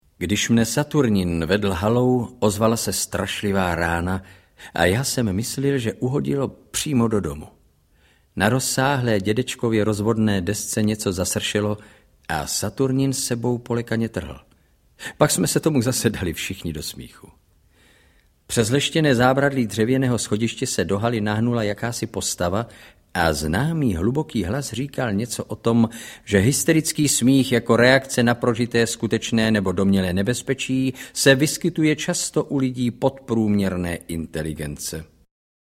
Saturnin audiokniha
Ukázka z knihy